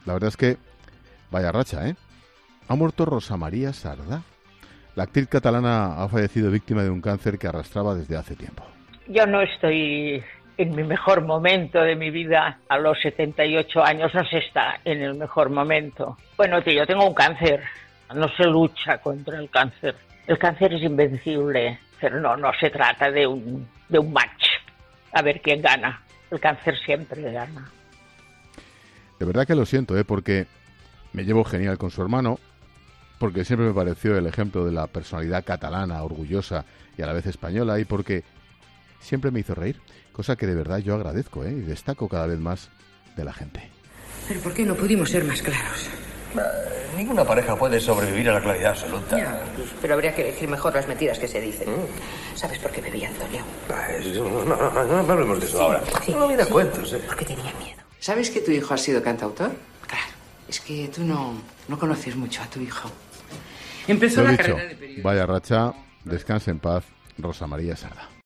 Ángel Expósito ha querido rendir homenaje a Rosa María Sardá para poner un broche inmejorable a su monólogo inicial en ‘La Linterna’ de este jueves.
“Yo no estoy en mi mejor momento de mi vida. A los 78 años no se está en el mejor momento. Bueno, yo tengo un cáncer. No se lucha contra el cáncer. El cáncer es invencible. No se trata de un ‘match’ a ver quién gana: el cáncer siempre gana”, contaba Sardá en una entrevista reciente, cuyo sonido ha sido rescatado para la ocasión por Expósito.